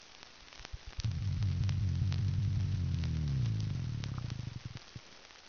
Sounds Made by Mycteroperca venenosa
Type of sound produced courtship associated sounds
Sound production organ swim bladder
Sound mechanism vibration by contraction of associated muscles (probably similar as in Epinephelus striatus)
Behavioural context courtship displays between males and females recorded in situ during the spawning aggregation in western Puerto Rico